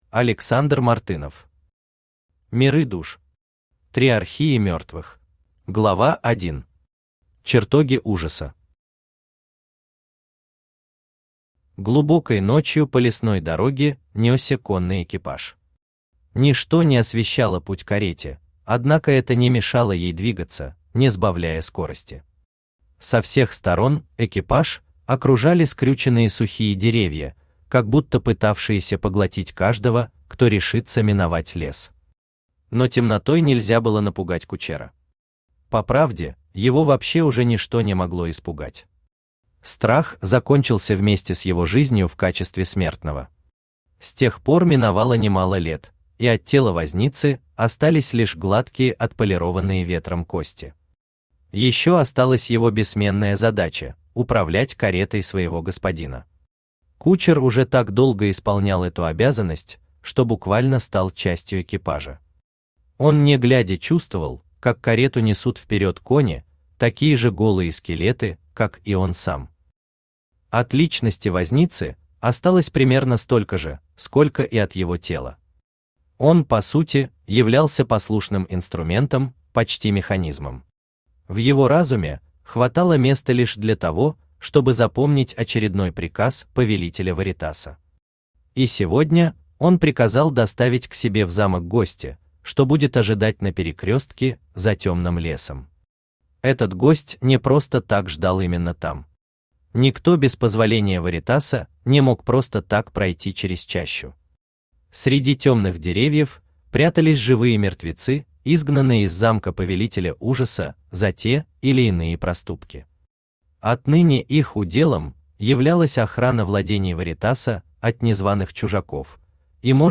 Триархия мертвых Автор Александр Мартынов Читает аудиокнигу Авточтец ЛитРес.